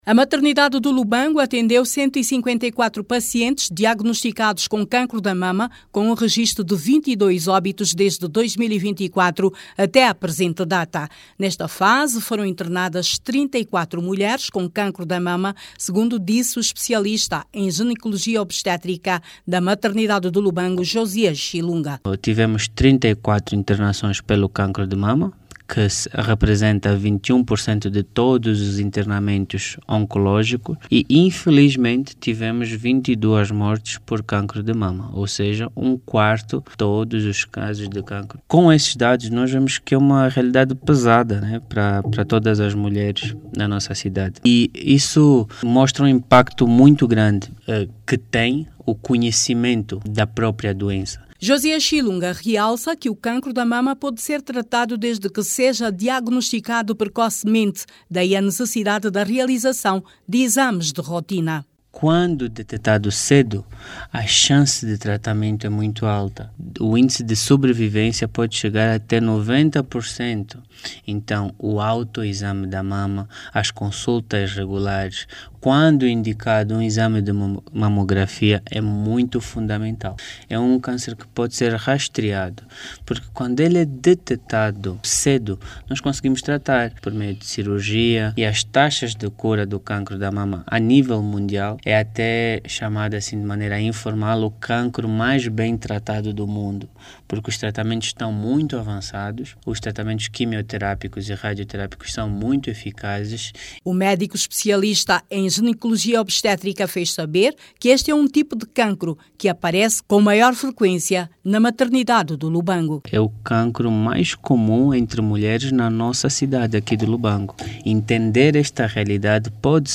O especialista em Ginecologia e Obstetrícia, contactado pela RNA na Huíla, aponta a chegada tardia das pacientes ao hospital para diagnóstico como a principal causa das mortes.